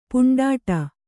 punḍāṭa